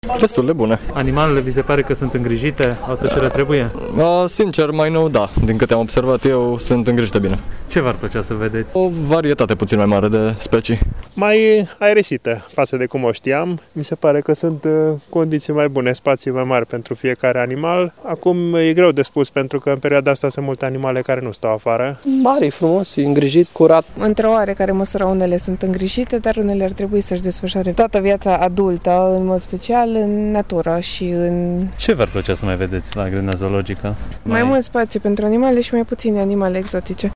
Majoritatea mureșenilor se arată mulțumiți de condițiile oferite de grădina zoologică și consideră că animalele sunt bine îngrijite: